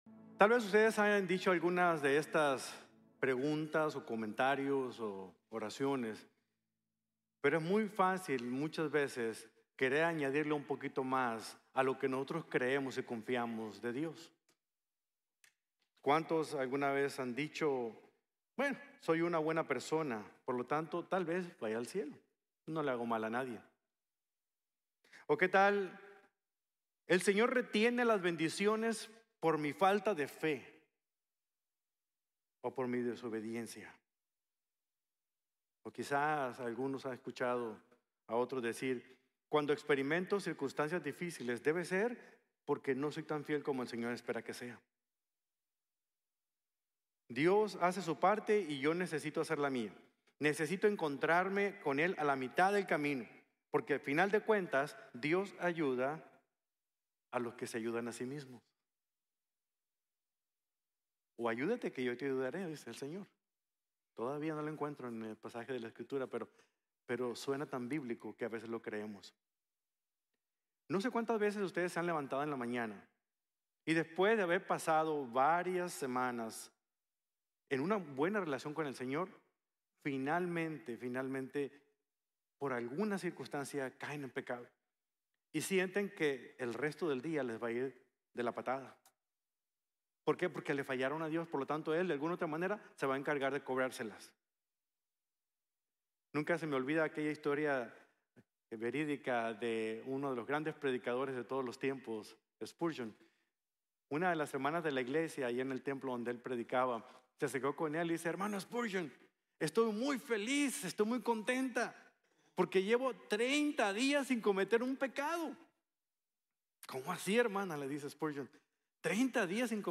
Rechazando a Babilonia | Sermón | Iglesia Bíblica de la Gracia